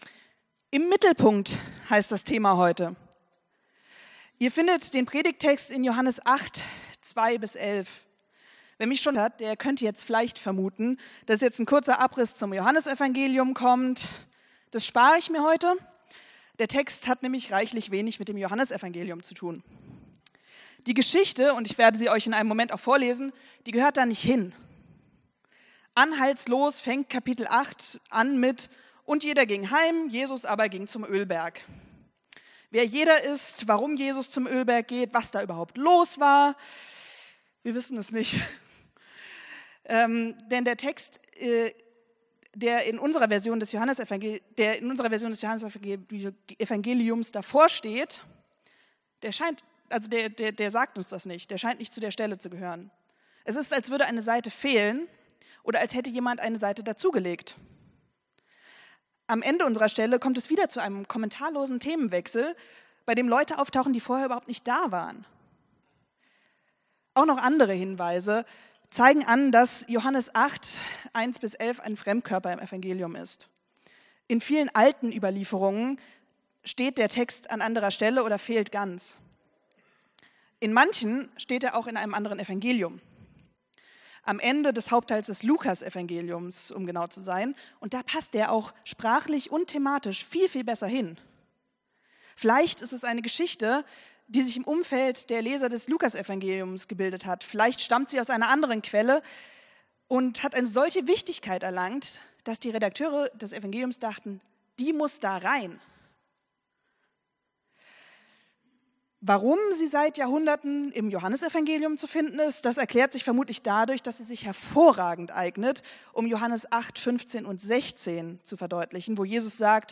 Predigt vom 28.07.2024